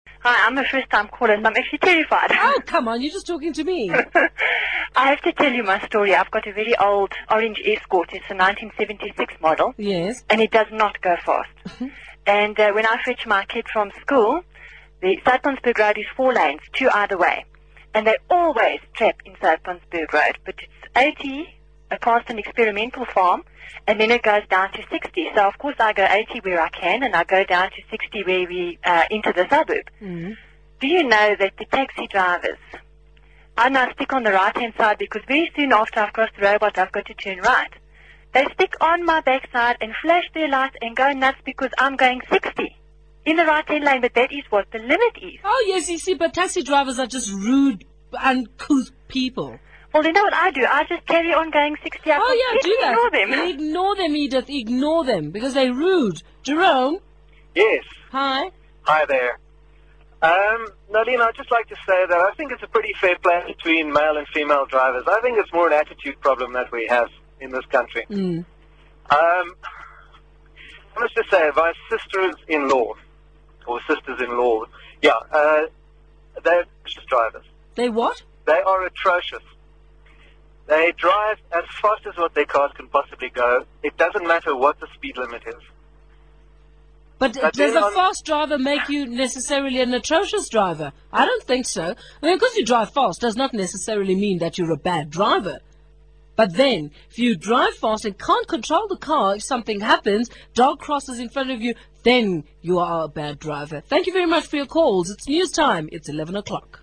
South African English
In general South African English is like other major varieties in the Southern Hemisphere and shares, for instance, the raising of short front vowels, e.g. [bɛd] for bad, [bɪd] for bed, with Australian and New Zealand English. A distinctive feature is the diphthong flattening in the PRICE lexical set, i.e. one has [prɑ:s] rather than [prɑɪs].